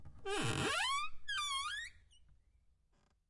门 - 声音 - 淘声网 - 免费音效素材资源|视频游戏配乐下载
描述：温和的风把门推进去了
标签： 吱吱嘎嘎 录音
声道立体声